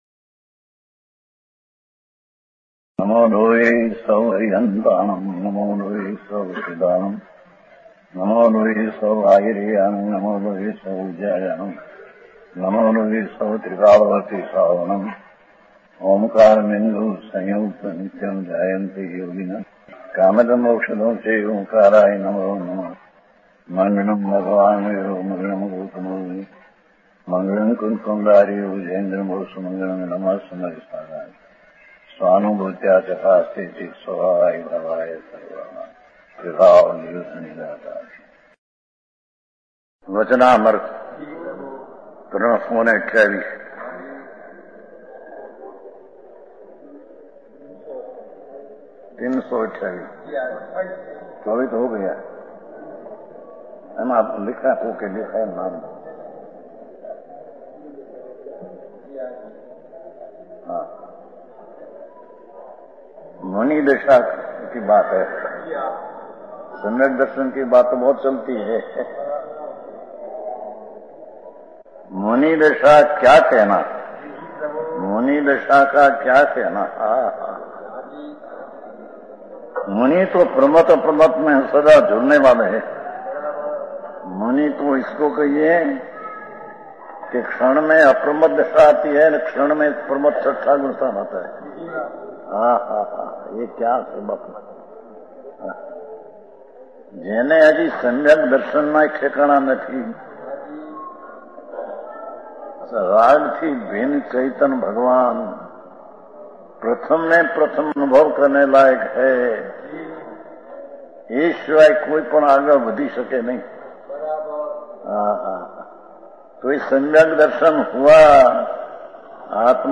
Bahenshree na Vachanaamrut Separately available Pravachan